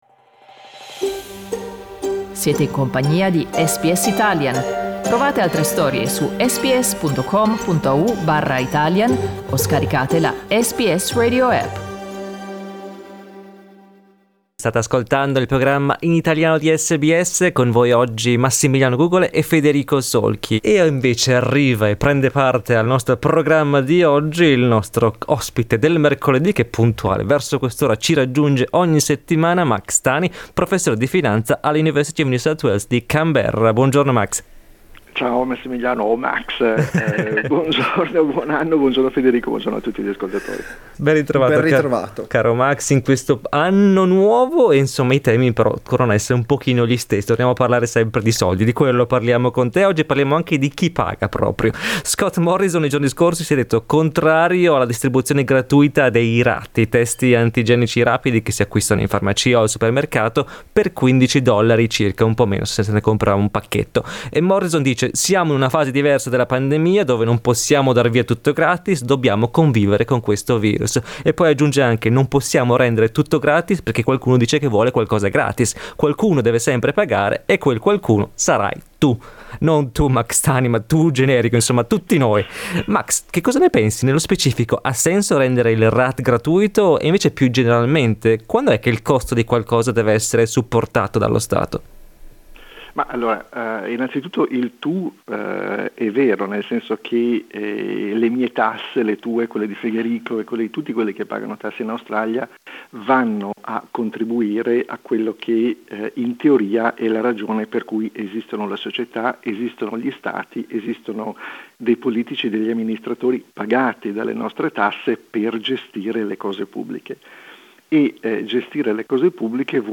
Ne abbiamo parlato con il professore di finanza